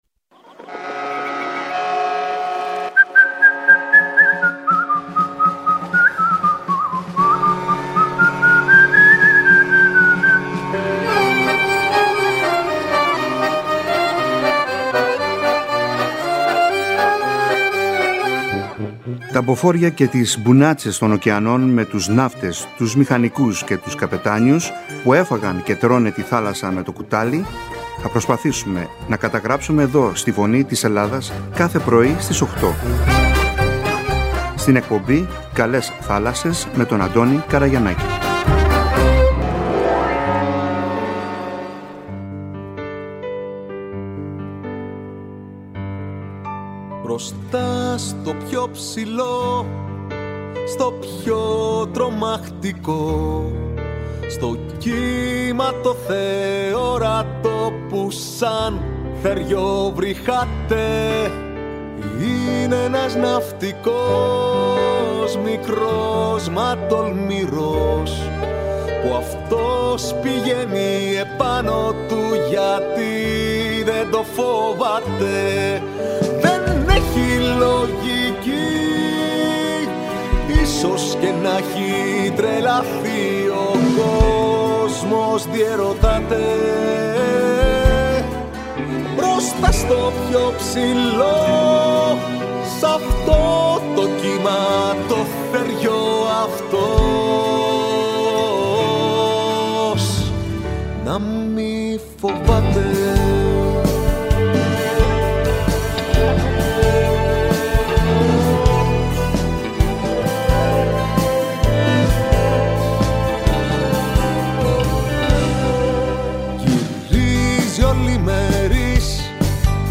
Εκπομπή για τους Έλληνες ναυτικούς με θέματα που απασχολούν την καθημερινότητά τους. Ιστορίες και μαρτυρίες ναυτικών και ζωντανές συνδέσεις με τα ελληνικά ποντοπόρα πλοία που ταξιδεύουν στις θάλασσες του κόσμου.